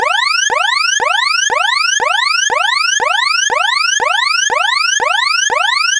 Alert Alarm